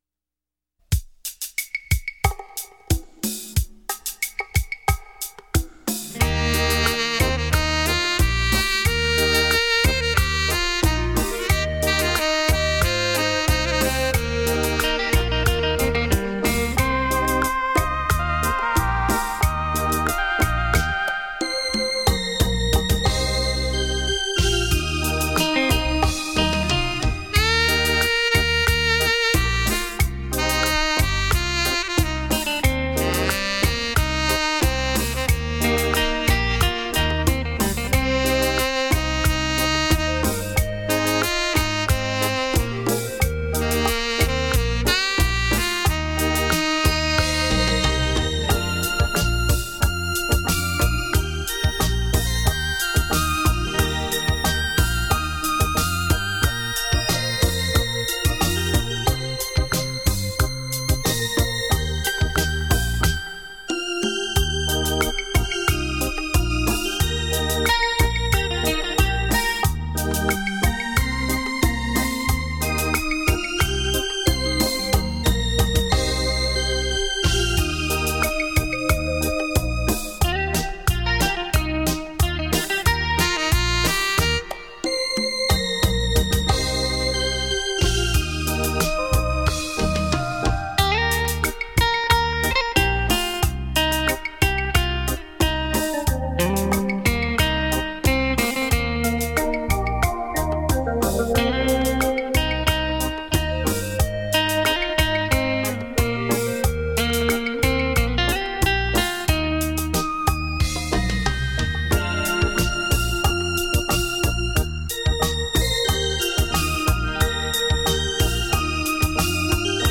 [音响测试带]